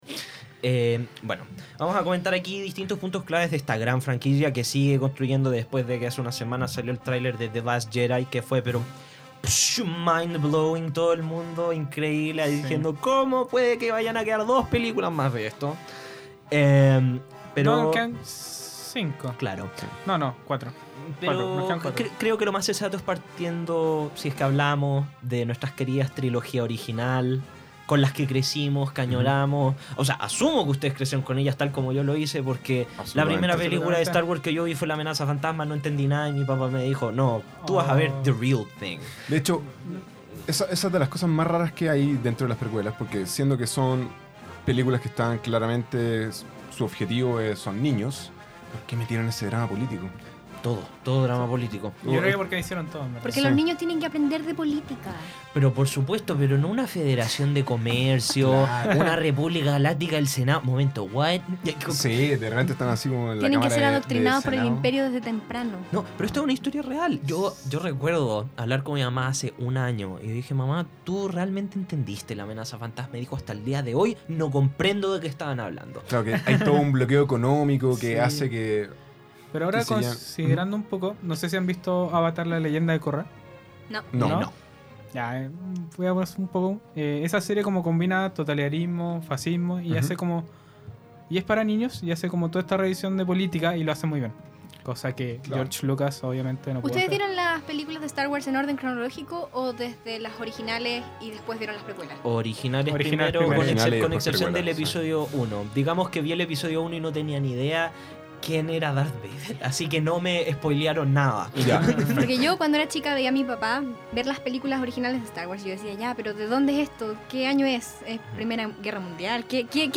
Aquí encontrarás charlas y exposiciones nutridas sobre diferentes ámbitos del cine,  grandes exponentes de la industria y películas que se encuentran en cartelera.
En este capítulo analizamos y debatimos sobre lo más interesante de esta obra cinematográfica, un clásico de la ciencia ficción que ha inspirado a generaciones y cautivado a sus espectadores alrededor del mundo. Films que nos traen nostalgia y fanatismo a nuestro estudio, aquí en Radio UC.